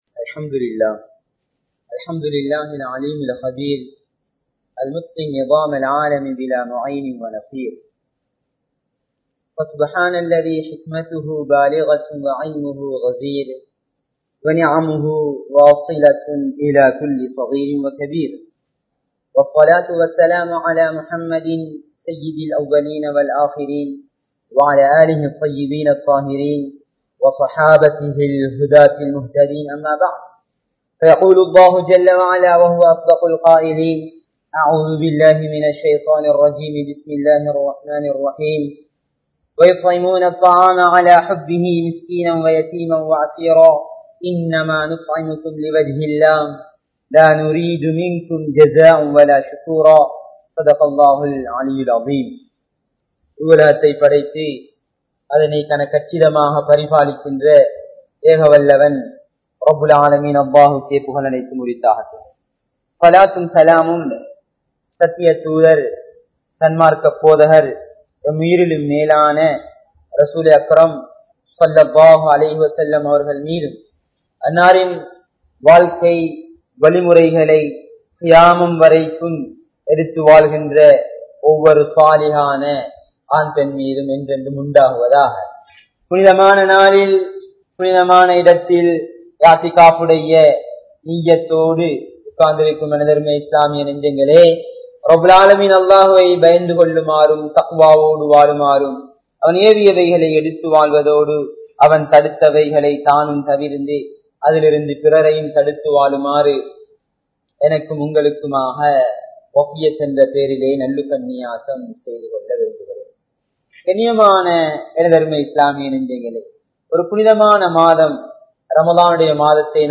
Haajihale! Ealaihalaium Paarungal (ஹாஜிகளே! ஏழைகளையும் பாருங்கள்) | Audio Bayans | All Ceylon Muslim Youth Community | Addalaichenai
Masjidun Noor Jumua Masjidh